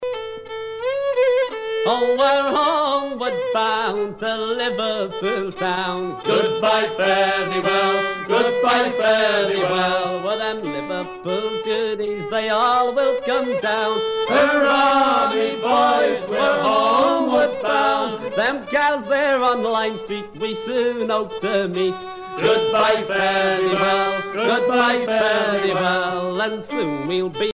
à virer au cabestan
Pièce musicale éditée